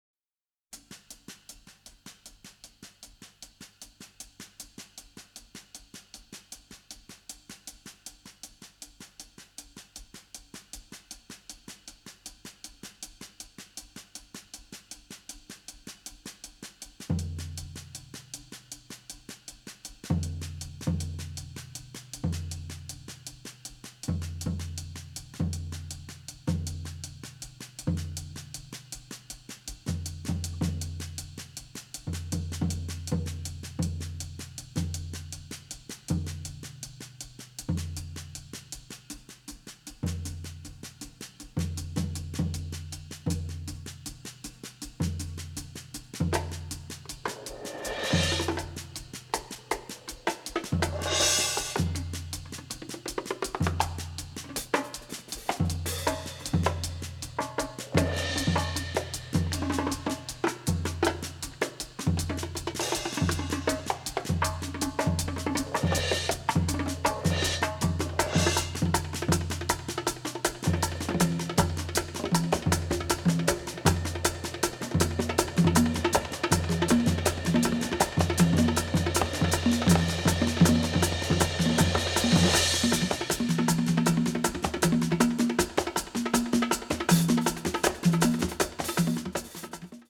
mixed in mono